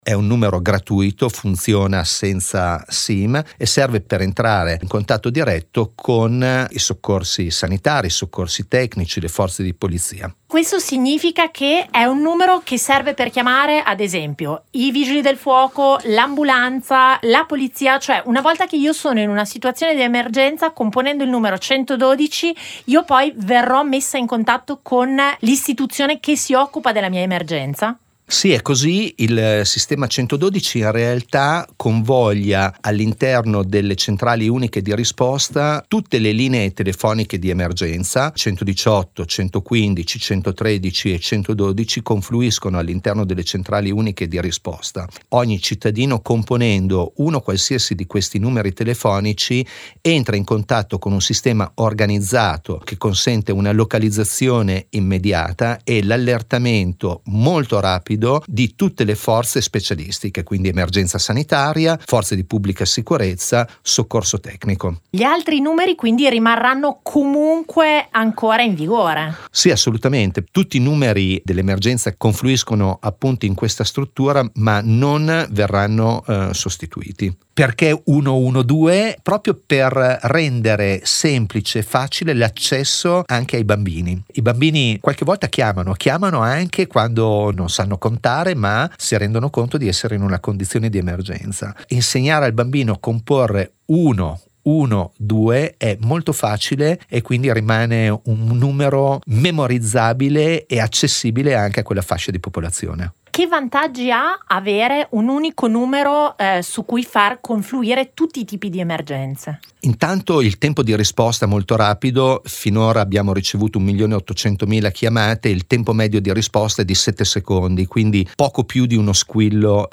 Home Magazine Interviste Attivo in tutta l’Emilia-Romagna il Numero Unico per le Emergenze 112: a...